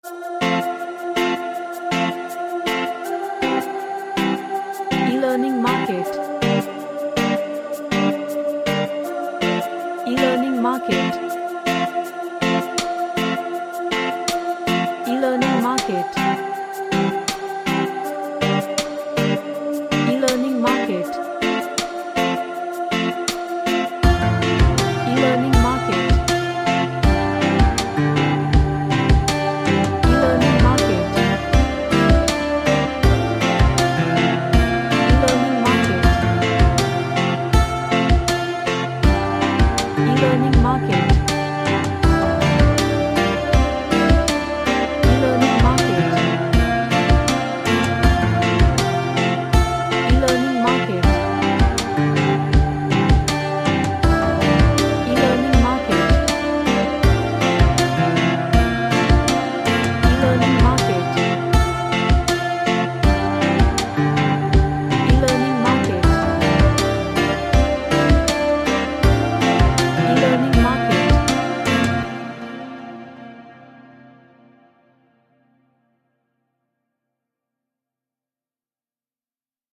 A Reggae track with perccusion and strings.
Happy